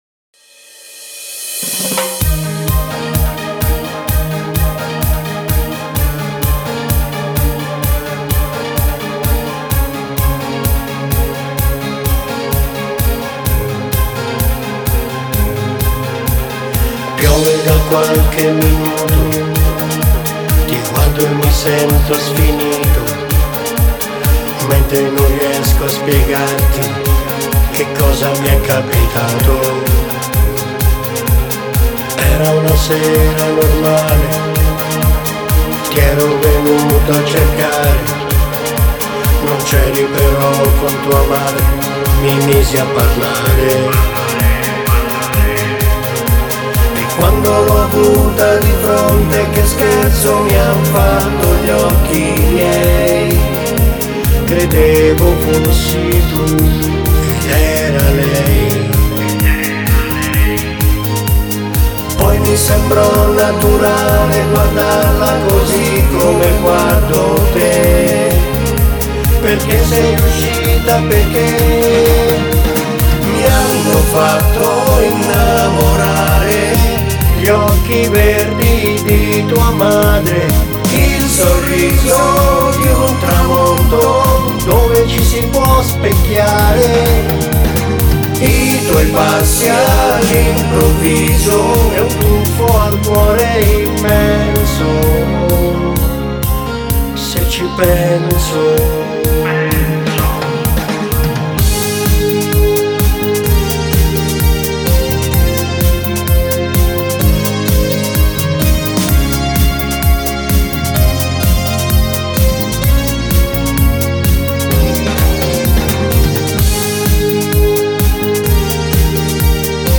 Ballo di gruppo